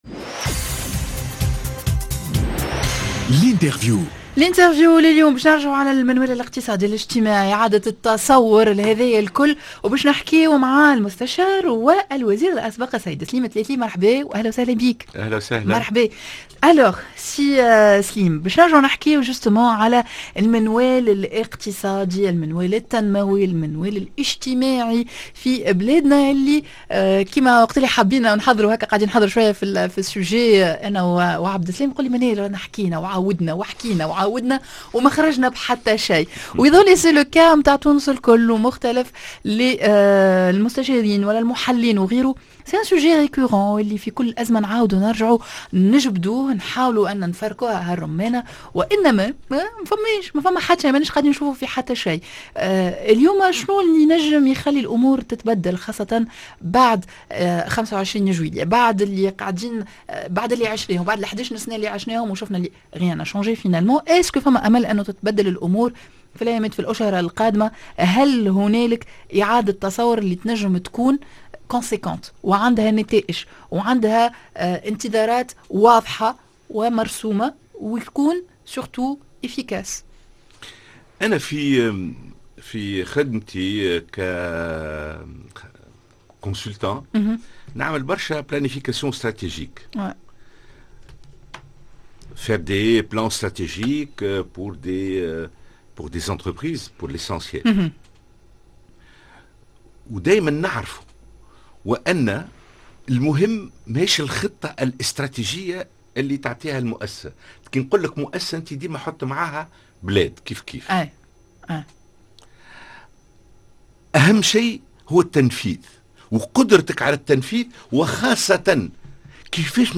اعادة تصوّر المنوال الاقتصادي و الاجتماعي هل هو الحلّ؟ سليم التلاتلي وزير أسبق و مستشار Interview Eco_Mag